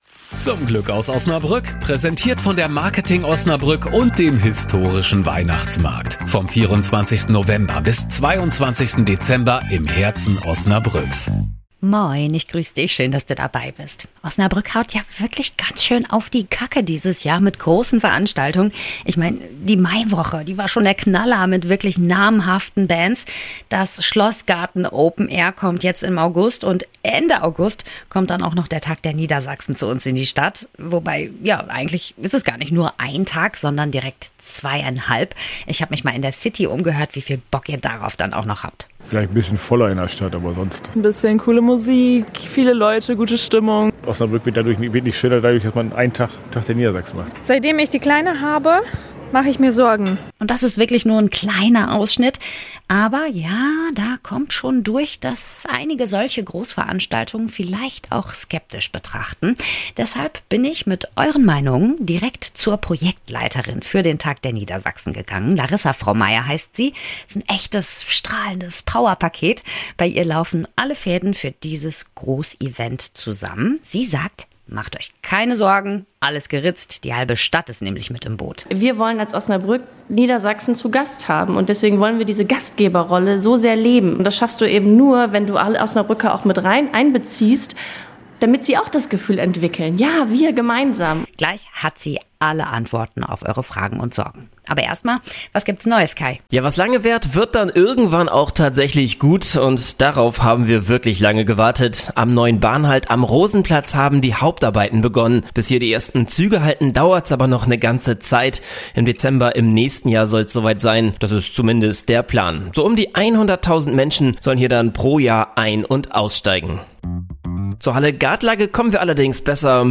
Vor meinem Gespräch habe ich mich bei uns in der City umgehört: Worauf freuen sich die Osnabrücker besonders? Aber auch: Welche Befürchtungen gibt es, z. B. beim Verkehrsaufkommen oder der Sicherheit?